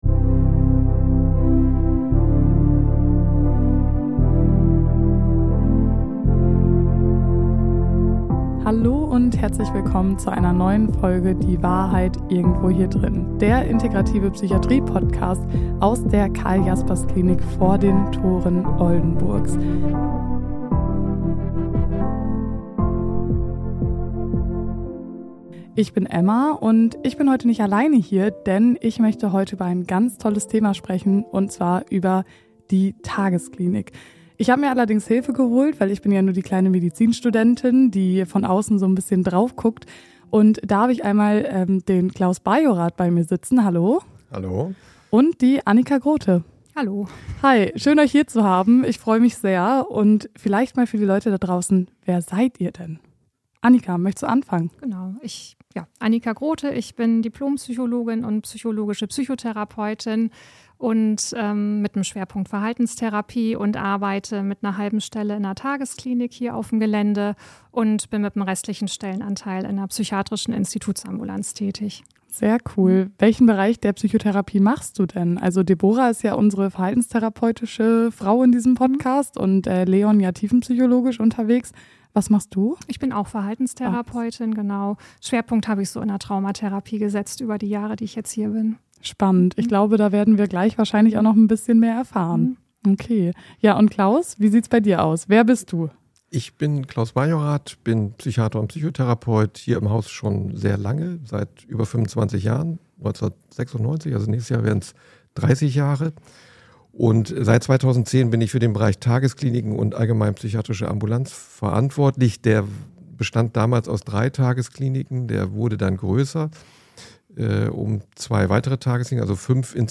#36 TAGESKLINIK Experten-Talk ~ Die Wahrheit Irgendwo Hier Drinnen Podcast